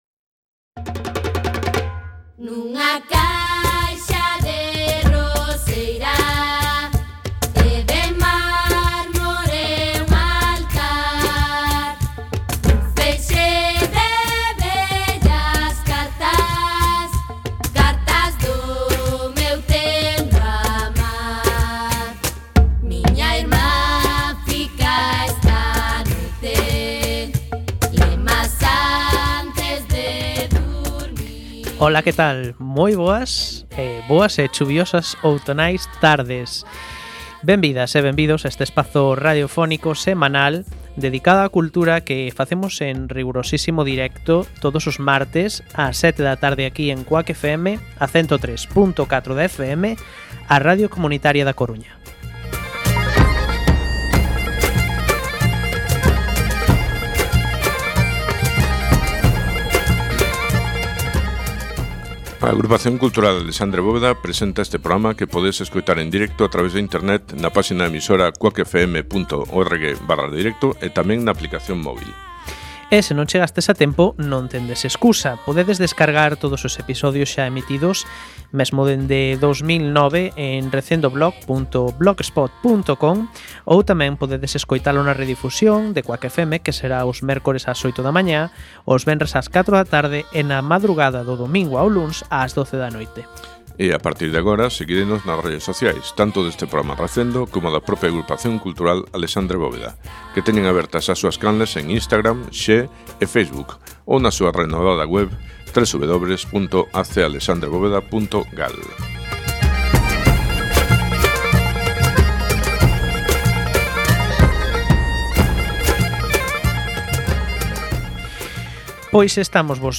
16x2 Entrevista